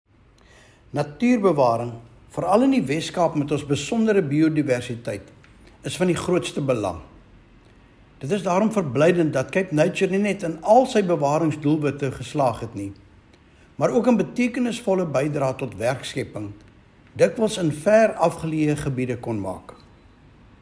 Afrikaans and English soundbites from MPP Andricus van der Westhuizen attached.